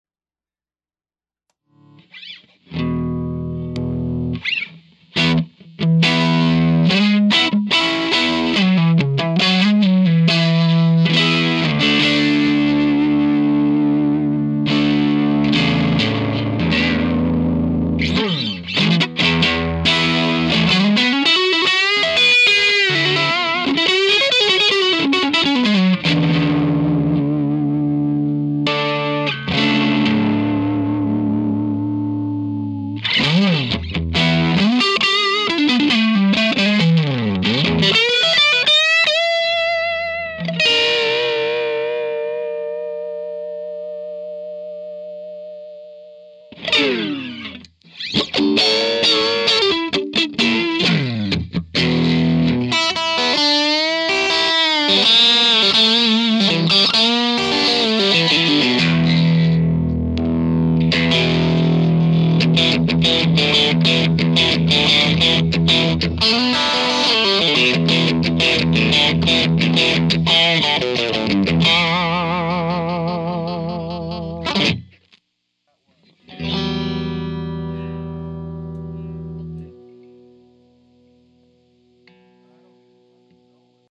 As requested, boosted clippage. The settings were the same for both clips. Boost on, bass at 6, Mid and treble at 7, volume at 7, master at 9.
Once again the setup is the same as before with a 2x12 with tone tubby ceramics in it.